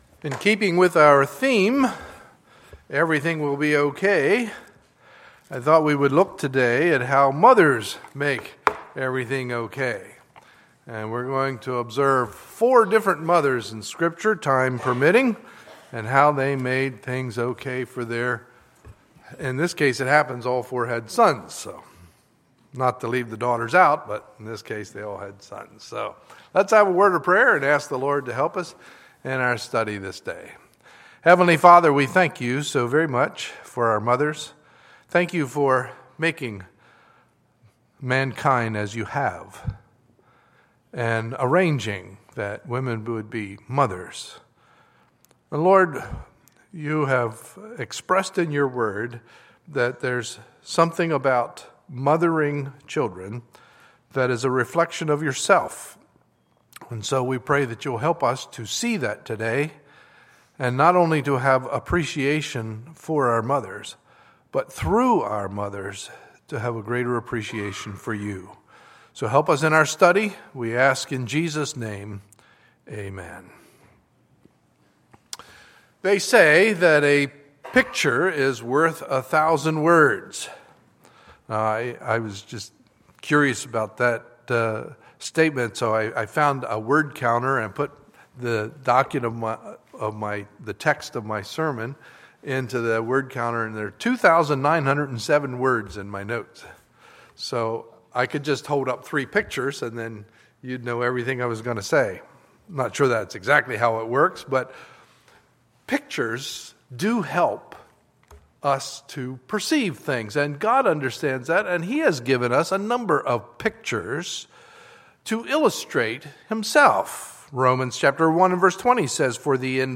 Sunday, May 10, 2015 – Sunday Morning Service